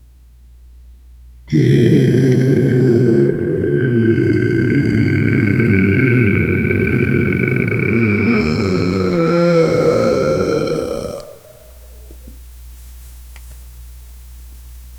No screams—pure suffering. 0:15 Created Apr 17, 2025 8:37 PM Deafening monster moans—guttural, wet, and agonized. Crackling throat, saliva sounds, violent tremolo, massive reverb. Unrelenting pain at full volume 0:15 Created Apr 17, 2025 8:39 PM
deafening-monster-moansgu-mt46cjxf.wav